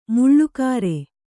♪ muḷḷu kāre